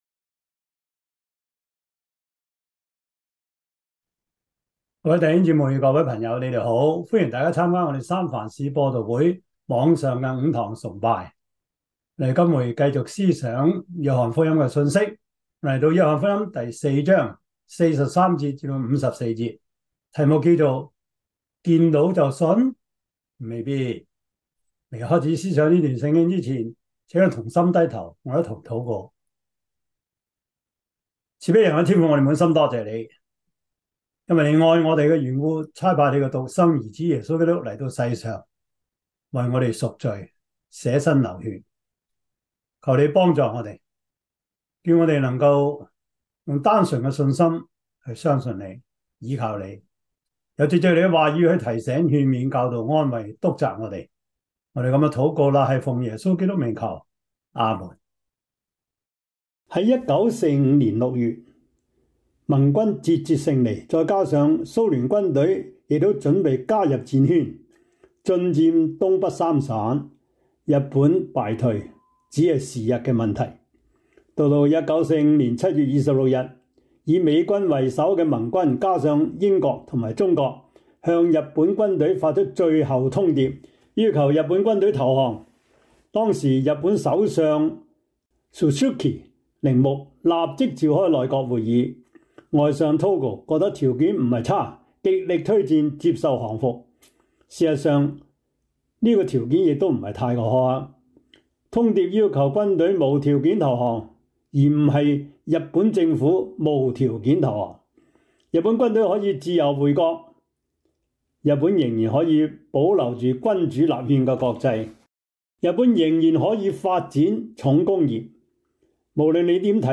約翰福音 4:43-54 Service Type: 主日崇拜 約翰福音 4:43-54 Chinese Union Version